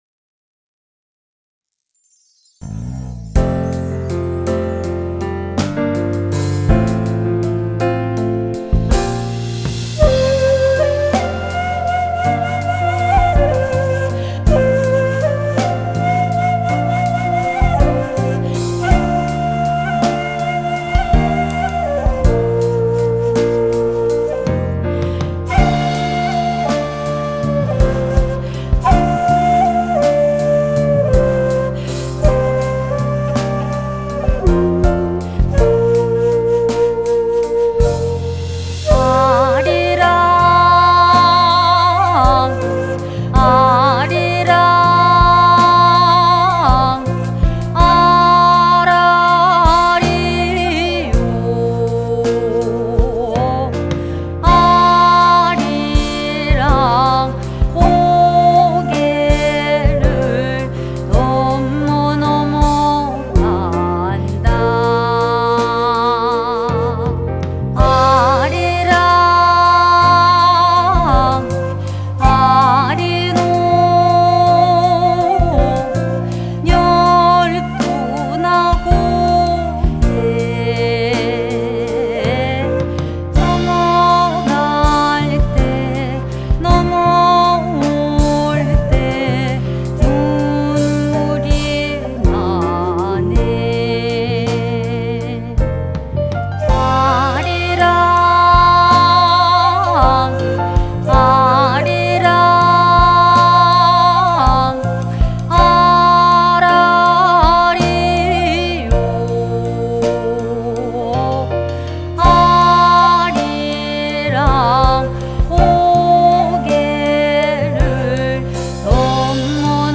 퓨전국악밴드 퀸이 2025년 버전으로 다시 부른 음원을 아래와 같이 제공합니다.